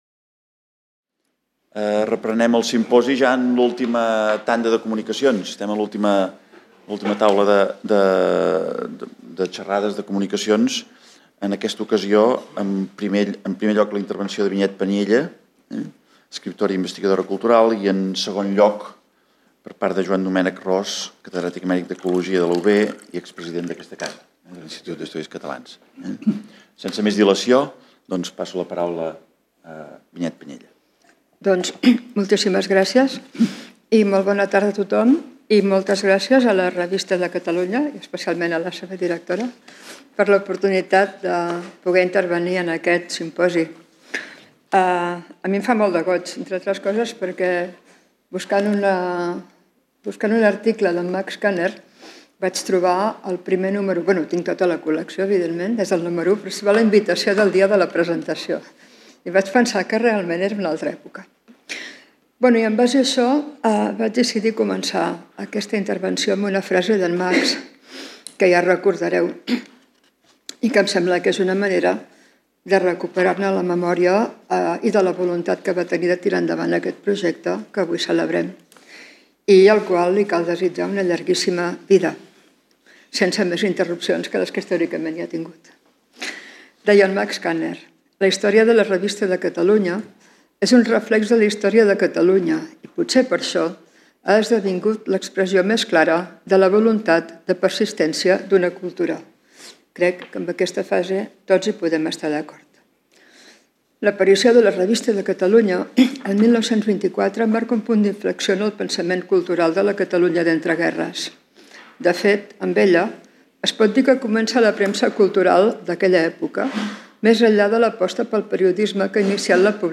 Comunicacions
en el marc del Simposi Trias 2024 sobre el centenari de la Revista de Catalunya